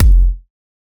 Index of /99Sounds Music Loops/Drum Oneshots/Twilight - Dance Drum Kit/Kicks